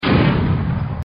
点赞音效礼物弹窗音效
Ui礼物弹窗炸弹.MP3